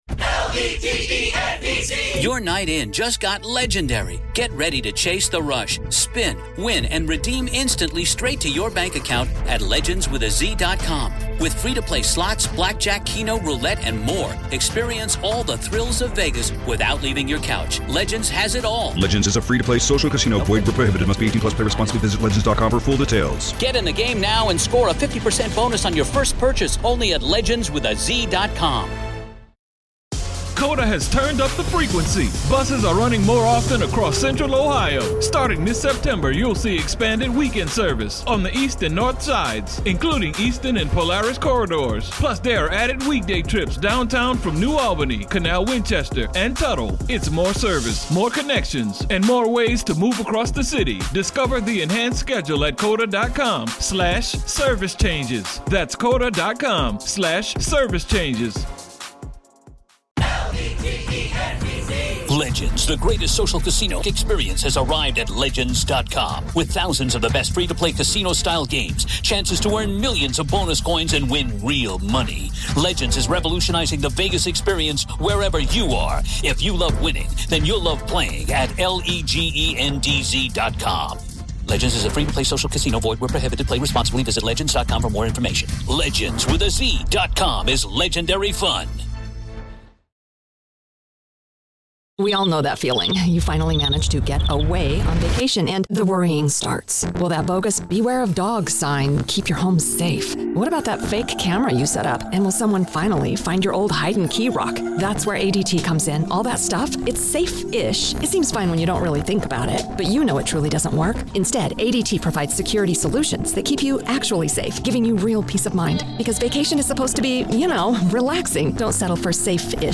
GUILTY-Murdaugh Strikes Plea Deal in Financial Crimes Case-RAW COURTROOM AUDIO PART 1